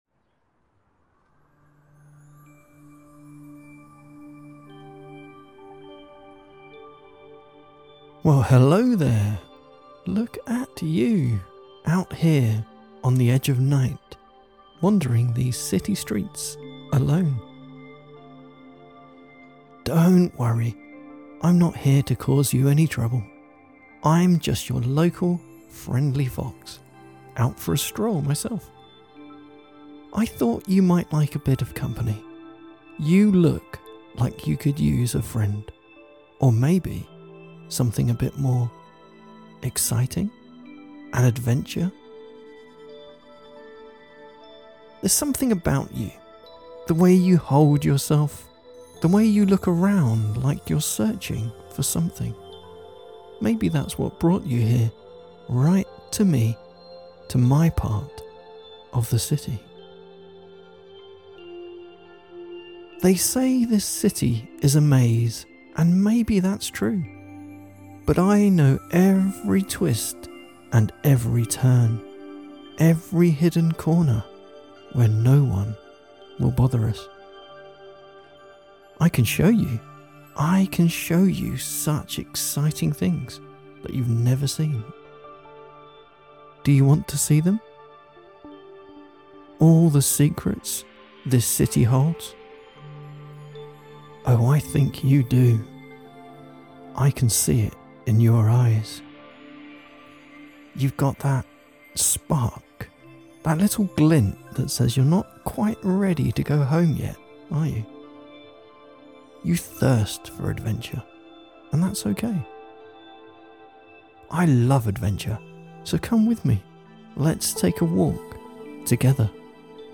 Relaxing and romantic guided sleep file, to help clear your mind of wandering thoughts and get you into a perfectly relaxed state for sleeping Script